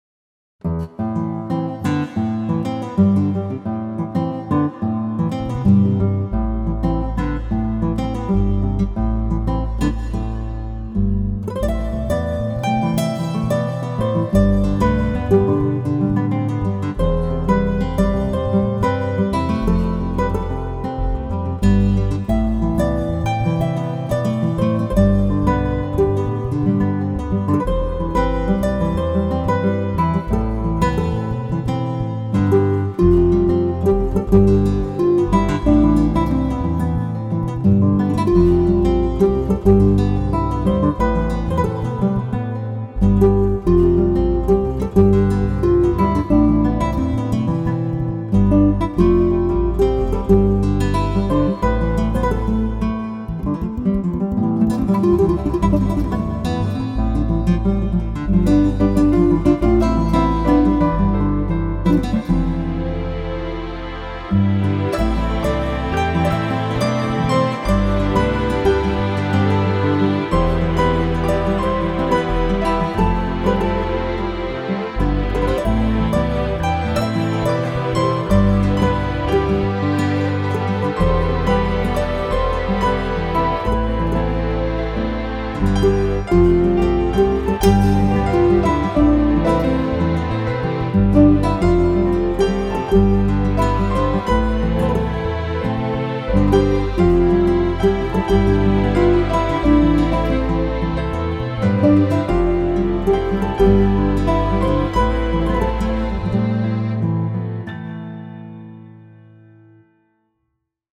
This is a remix